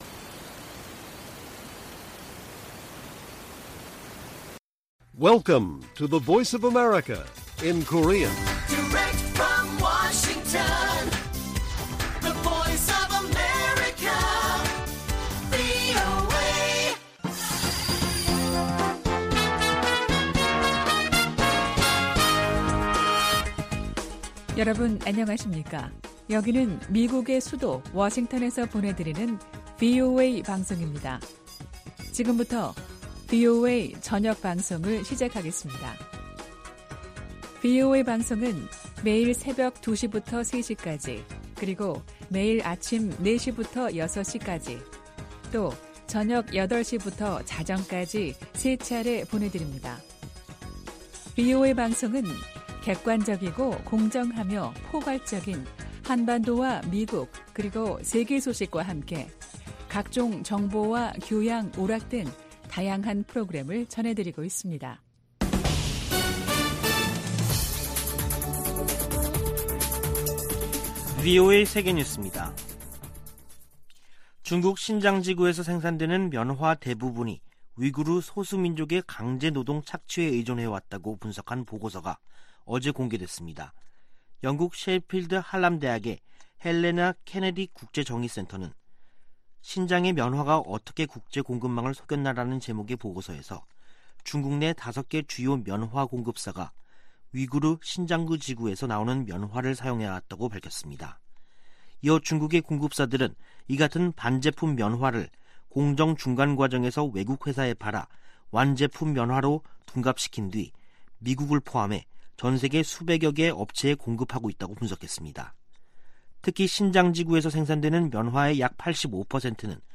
VOA 한국어 간판 뉴스 프로그램 '뉴스 투데이', 2021년 11월 24일 1부 방송입니다. 국제사회의 세부 안건들을 다루는 유엔총회 6개 위원회가 올해도 북한 문제를 놓고 각국이 설전을 벌였습니다. 미국 의회조사국이 북한의 사이버 공격을 진전되고 지속되는 위협으로 분류했습니다. 미국 국무부는 북한 당국이 최근 유엔의 북한인권 결의안 채택에 반발한 데 대해, 북한 인권 상황은 여전히 끔찍하다고 지적했습니다.